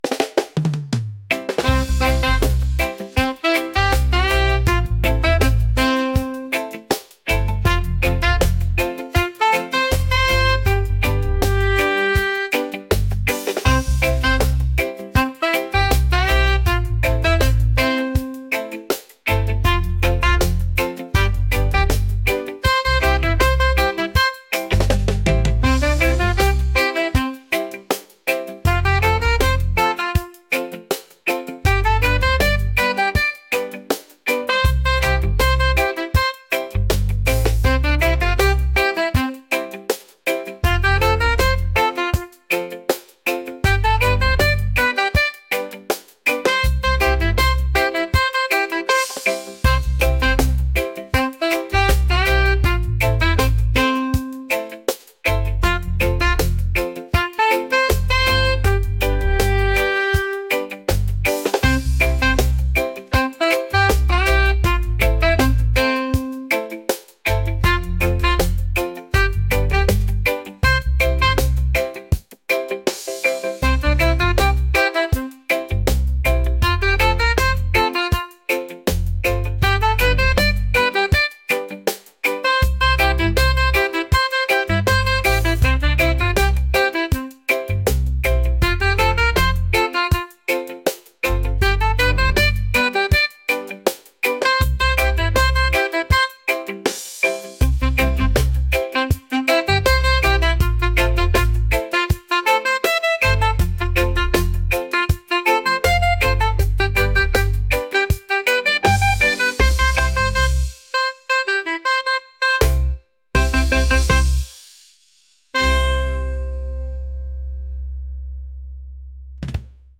energetic | reggae